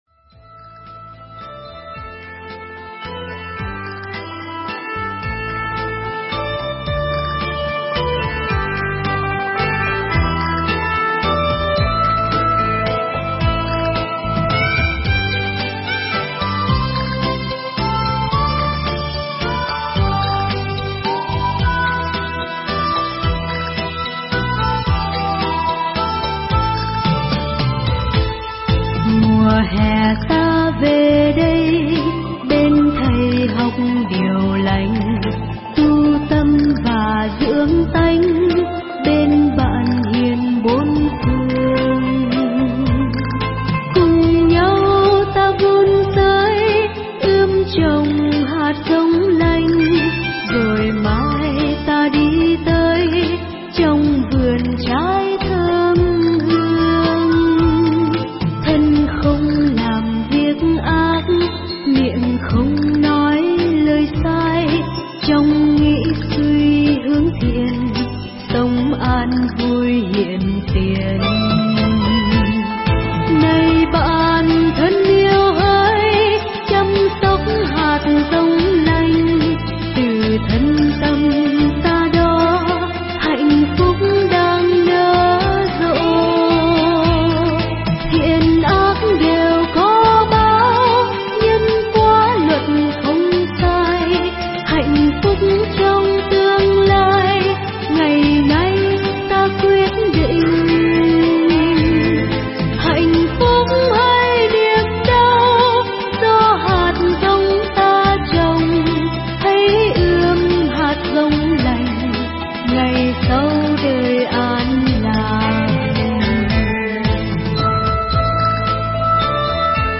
Mp3 Thuyết Pháp Những con đường tu tập
tại Chùa Ưu Đàm, Las Vegas, USA